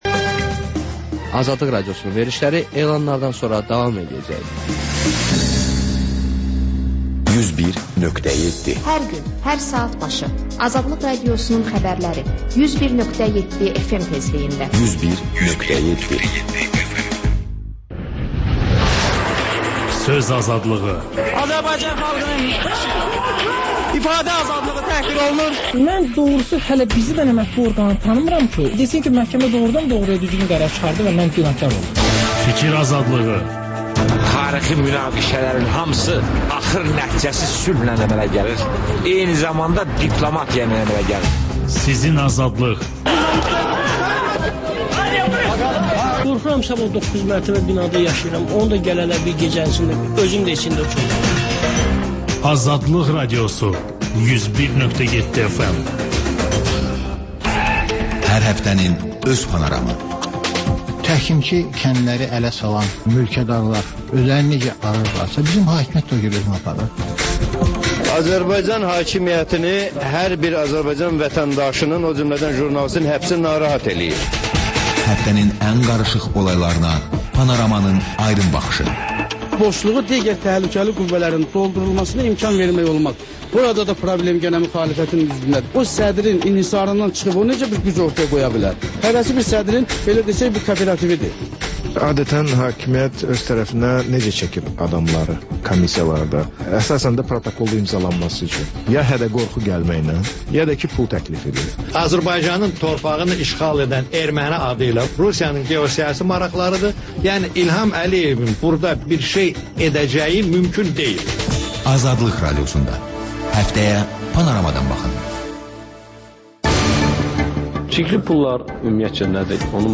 Xəbərlər, QAFQAZ QOVŞAĞI: «Azadlıq» Radiosunun Azərbaycan, Ermənistan və Gürcüstan redaksiyalarının müştərək layihəsi, sonda QAYNAR XƏTT: Dinləyici şikayətləri əsasında hazırlanmış veriliş